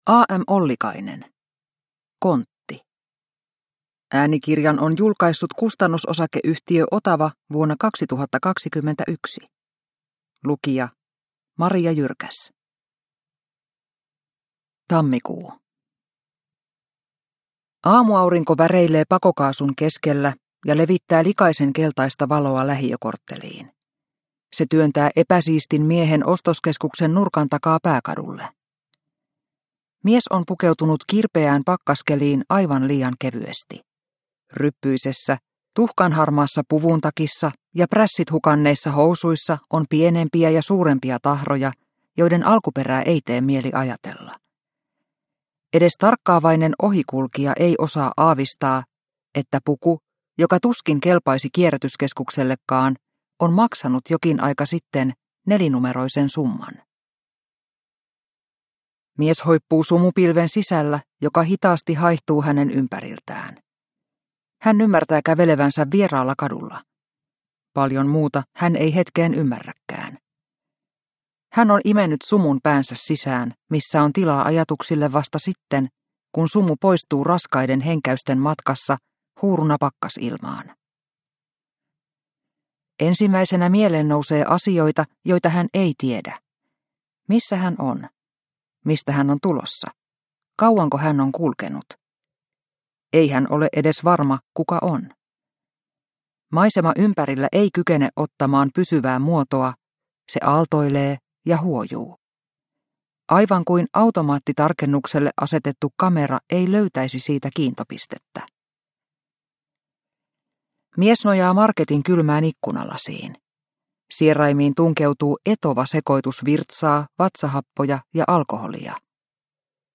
Kontti – Ljudbok – Laddas ner